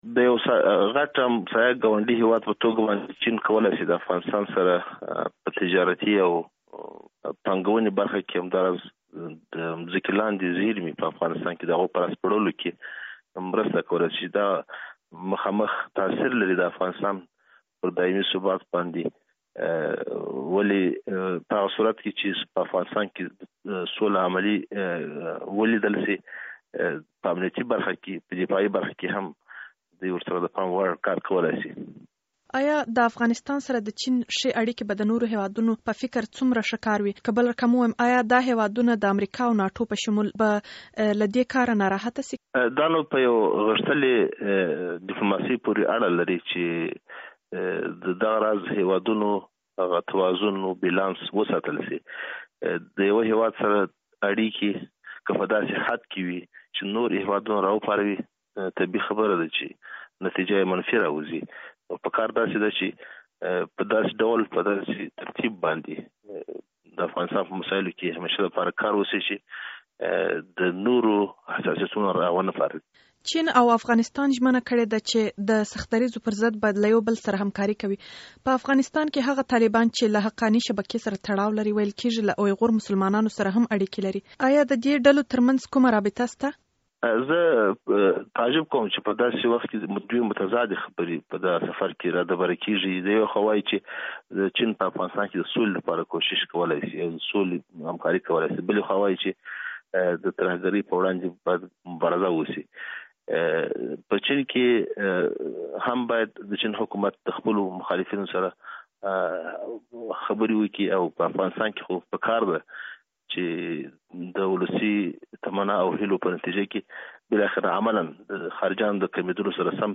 له وکیل احمد متوکل سره مرکه